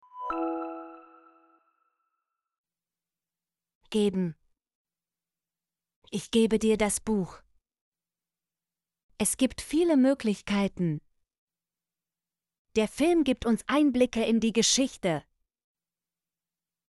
geben - Example Sentences & Pronunciation, German Frequency List